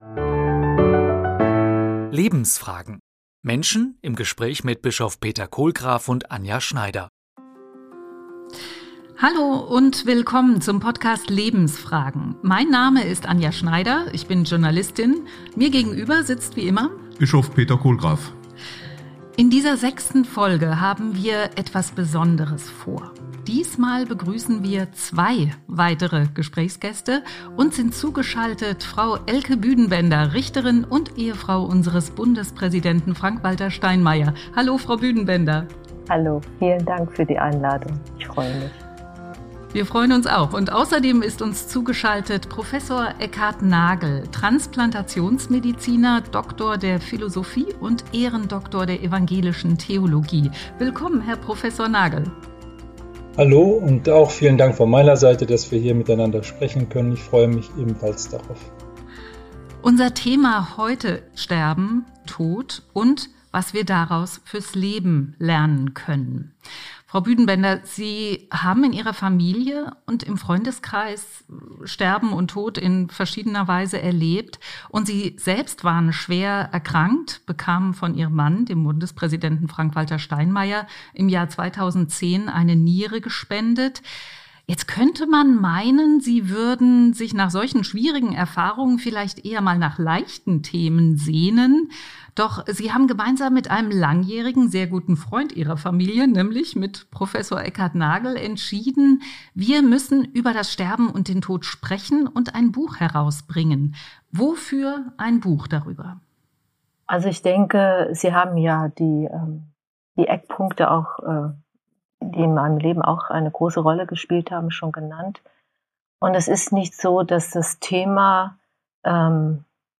Zu Gast: Elke Büdenbender und Prof. Eckhard Nagel. Sterben und Tod - und was wir davon für unser Leben lernen können: Darum geht es in der sechsten Folge des Podcasts „Lebensfragen“ aus dem Bistum Mainz.